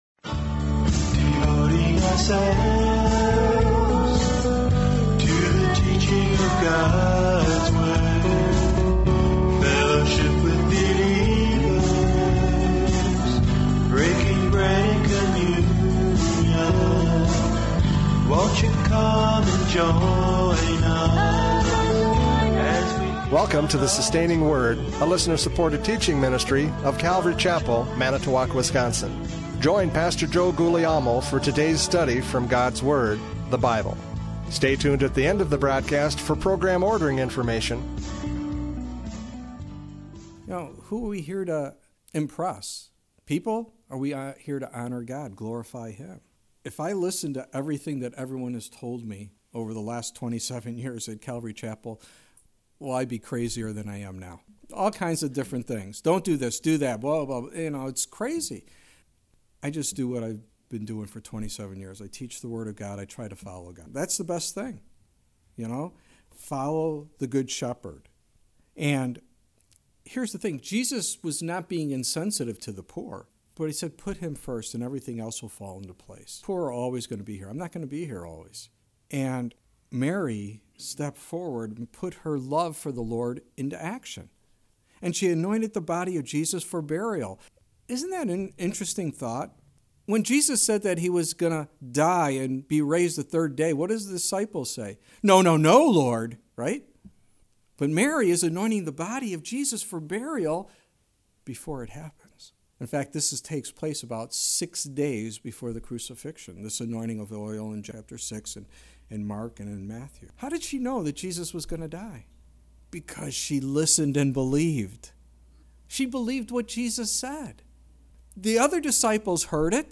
John 11:1-4 Service Type: Radio Programs « John 11:1-4 The Glory of God!